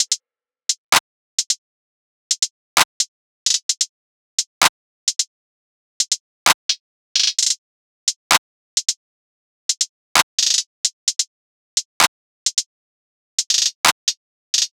SOUTHSIDE_beat_loop_lime_top_02_130.wav